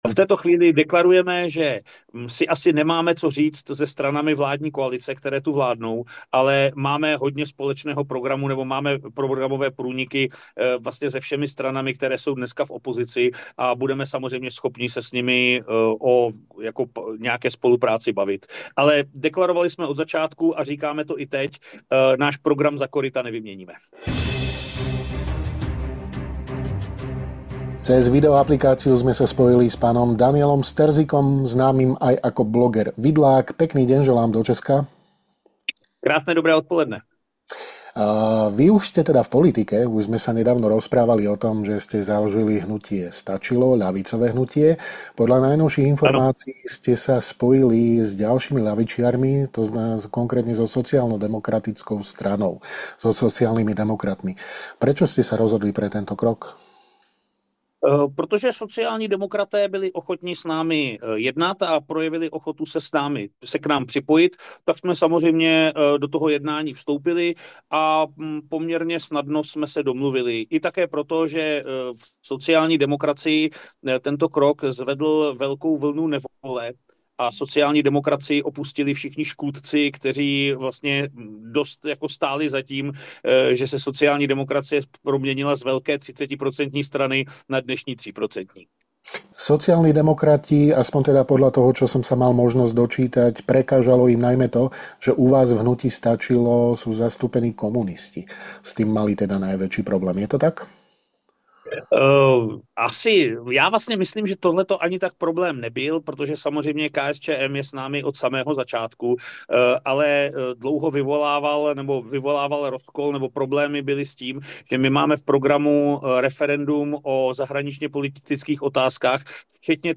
Viac v rozhovore.